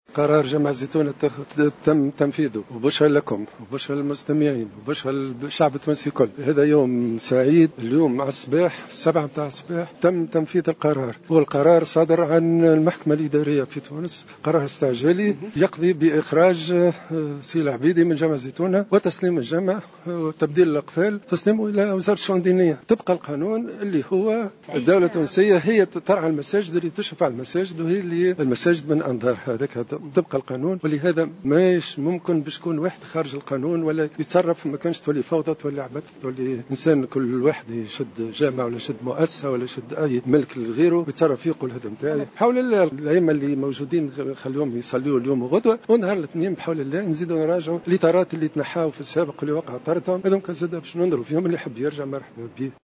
وأضاف الوزير في تصريح لجوهرة أف أم